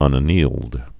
(ŭnə-nēld)